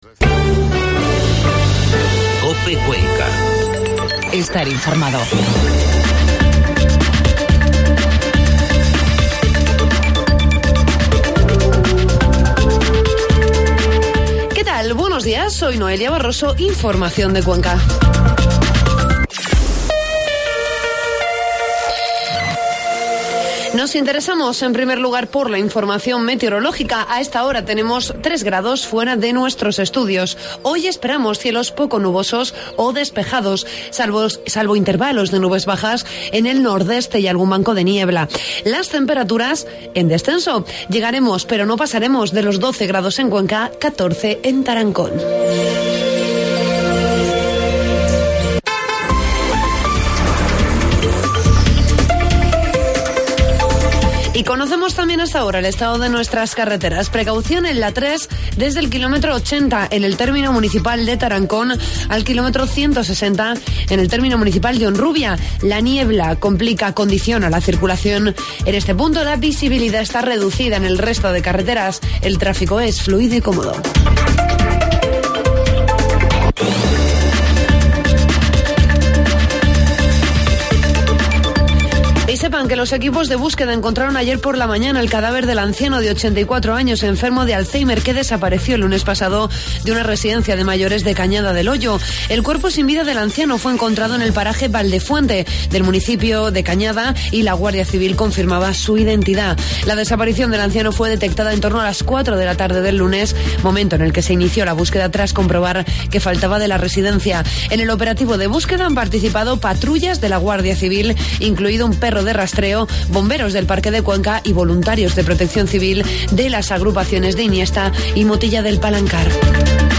Informativo matinal COPE Cuenca 6 de noviembre